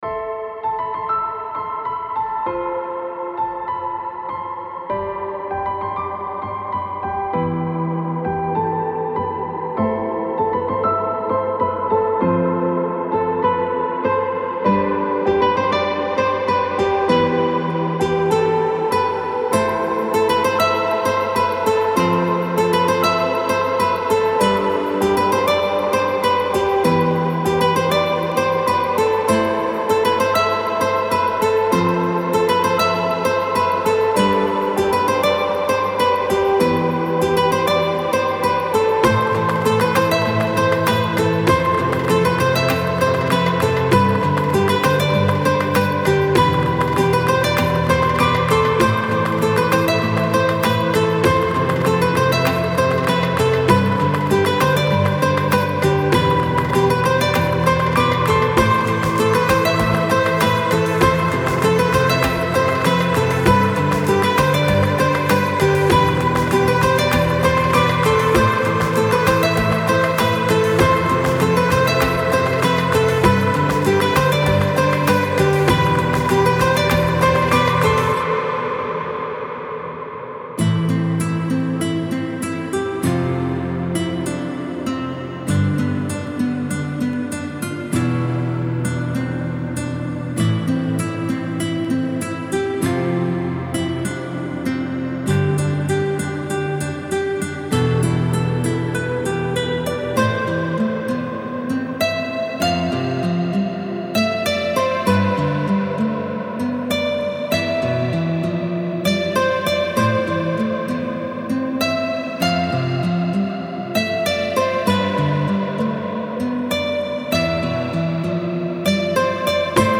سبک عاشقانه